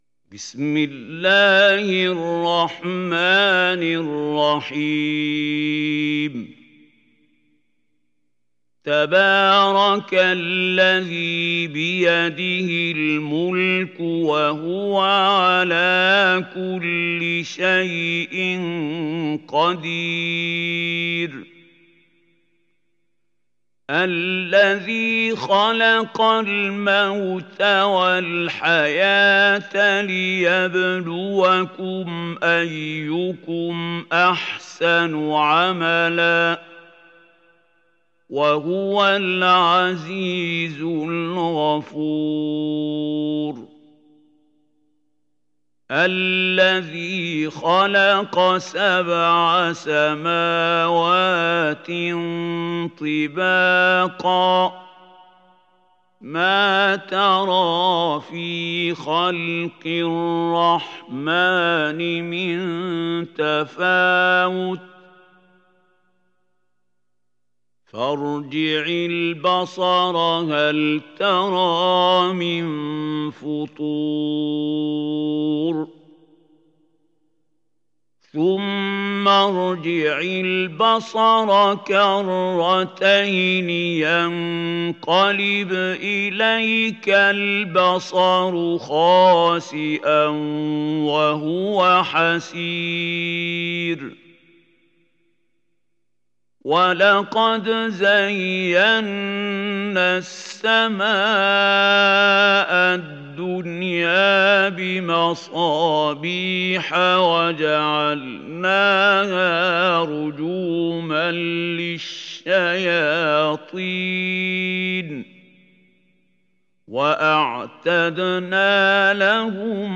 Mülk Suresi İndir mp3 Mahmoud Khalil Al Hussary Riwayat Hafs an Asim, Kurani indirin ve mp3 tam doğrudan bağlantılar dinle